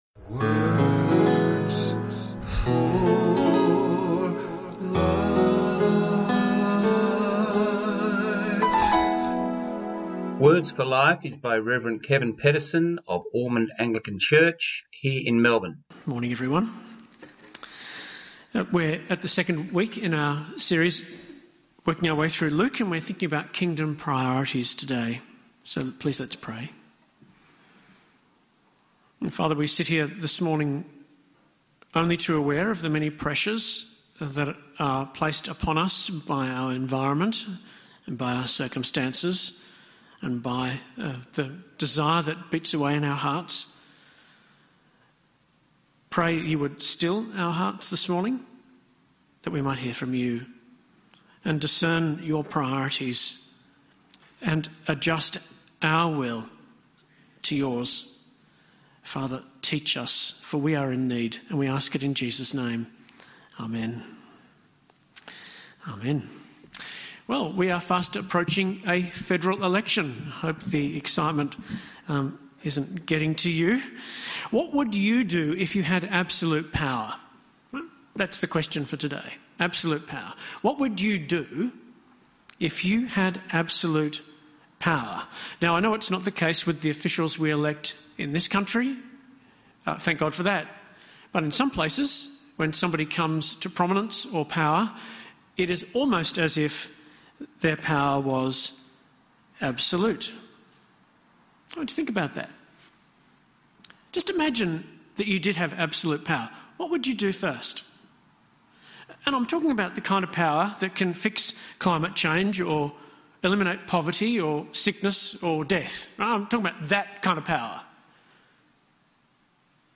sermons – Page 2 – Songs of Hope
His talk title was “Promises and Prayers” and is about Zachariah and the birth of John the Baptist. Program is Songs of Hope on Southern FM 88.3. Click the play button for the audio.